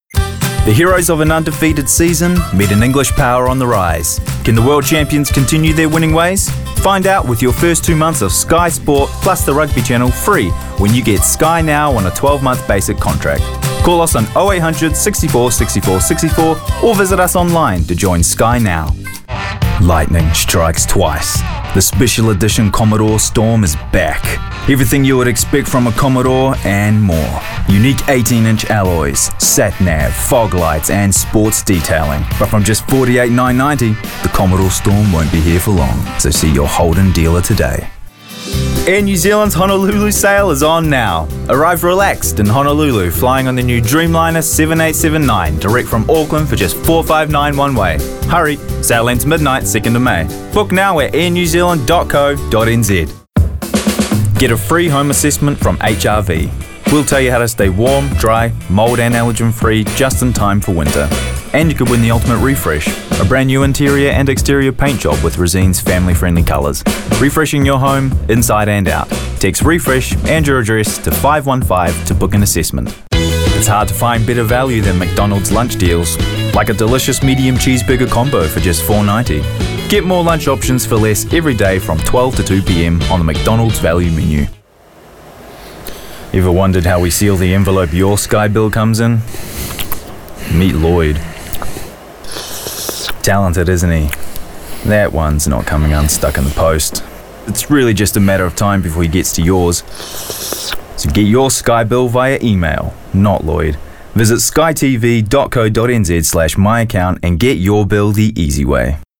Demo
Adult
australian | character
british rp | character
irish | character
midwestern us | character
new zealand | natural
standard us | character
cinema trailer
smooth/sophisticated
warm/friendly
creatures
efforts